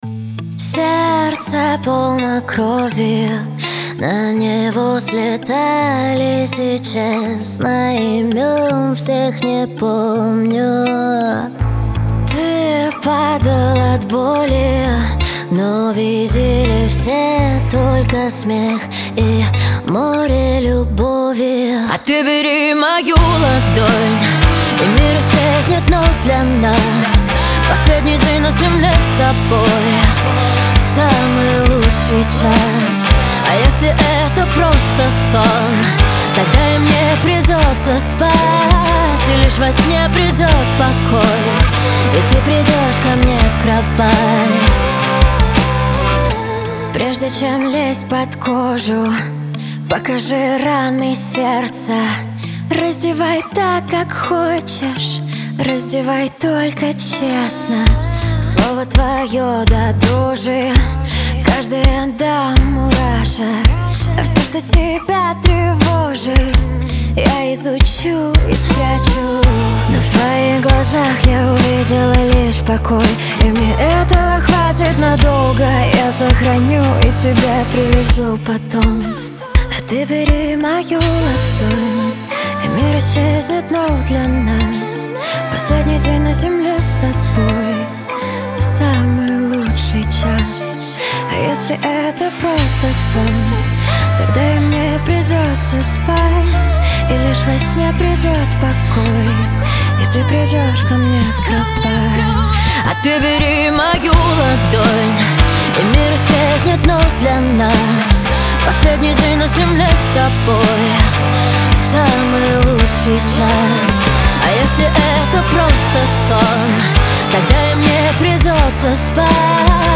Лирика
эстрада , танцы